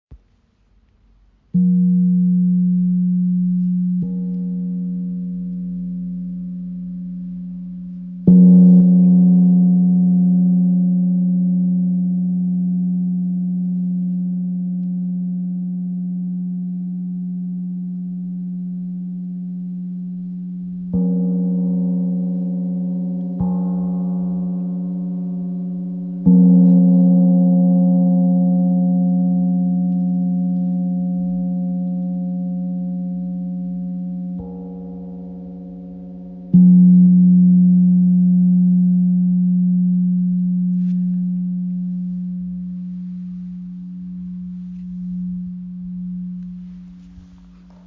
Tam Tam Gong | Chao Gong | Ø 40 cm im Raven-Spirit WebShop • Raven Spirit
Klangbeispiel
Der Sound ist voluminös und erinnert im Aufbau an übereinander liegende Klangebenen. So kann ein Soundgebilde aus Bass und Obertönen entstehen. Solche Gongs mit Rand erzeugen einen sehr meditativen Gesamtklang.